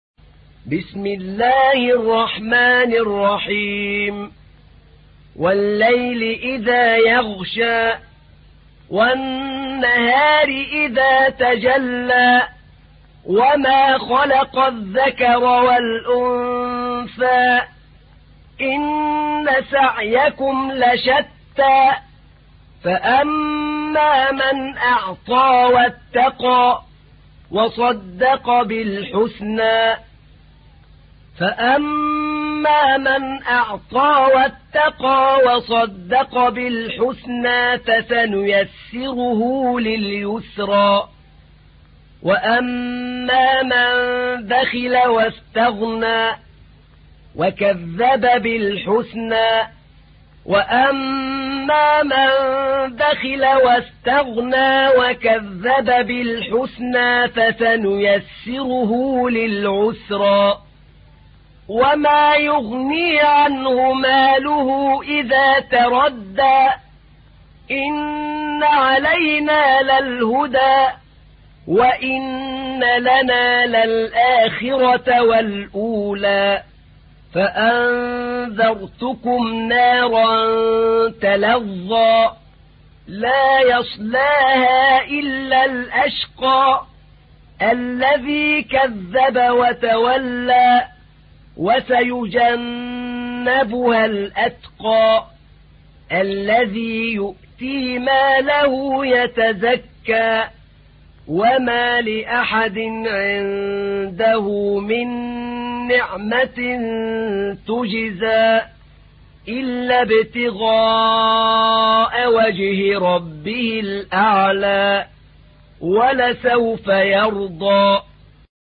تحميل : 92. سورة الليل / القارئ أحمد نعينع / القرآن الكريم / موقع يا حسين